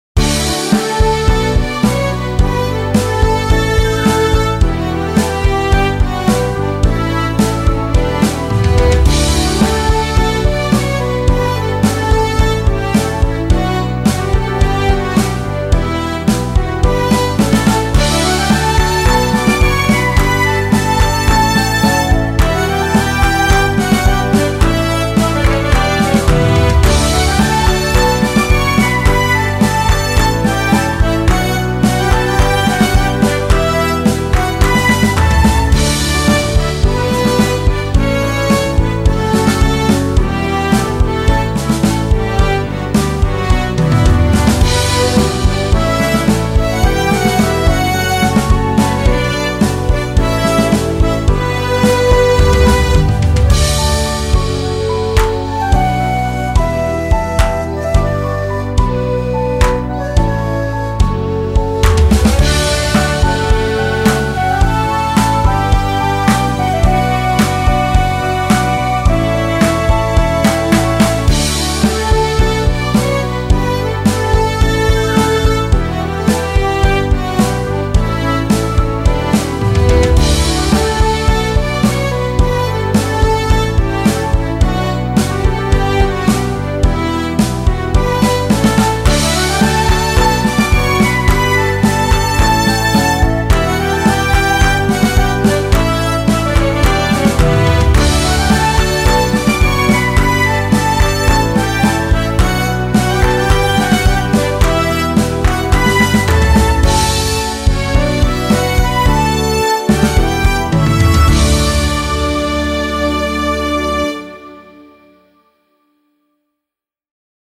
明るい雰囲気、賑やかな雰囲気を出したいシーンでいかがでしょうか。